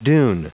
Prononciation du mot dune en anglais (fichier audio)